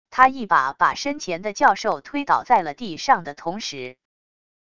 他一把把身前的教授推倒在了地上的同时wav音频生成系统WAV Audio Player